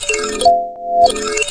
SI2 THUMP.wav